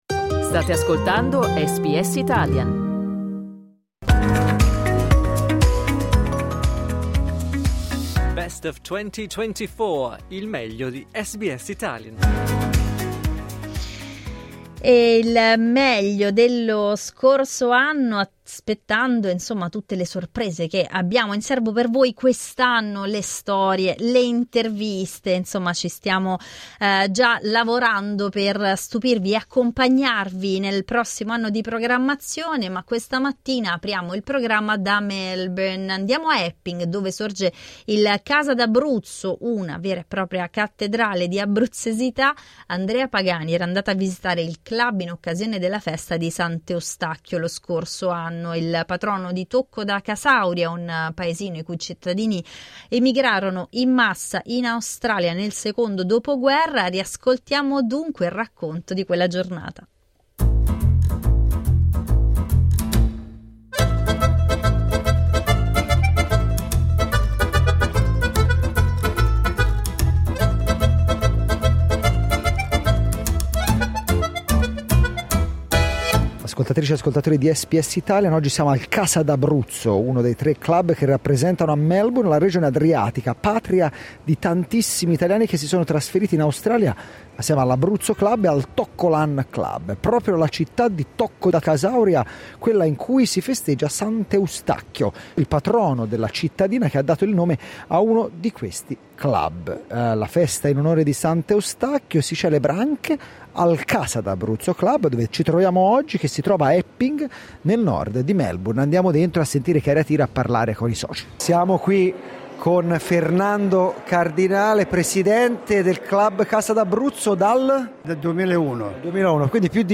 Dai nostri archivi, una serata al club che si trova a Epping, a nord di Melbourne.